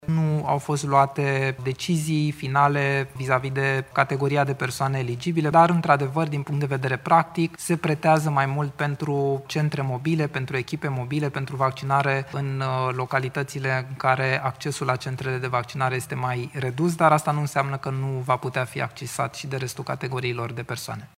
Acesta ar putea fi folosit mai ales de echipele mobile pentru vaccinarea în localitățile greu accesibile, spune președintele Comitetului Național de Vaccinare Valeriu Gheorghiță: